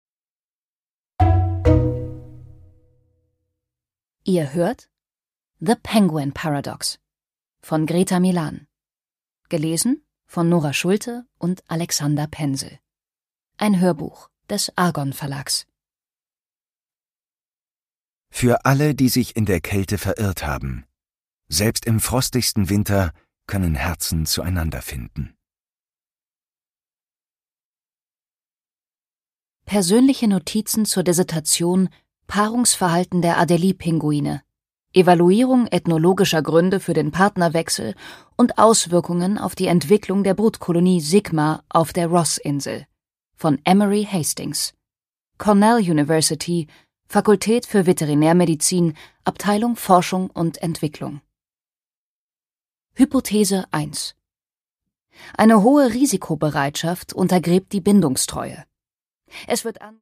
Greta Milán: The Penguin Paradox (Ungekürzte Lesung)
Produkttyp: Hörbuch-Download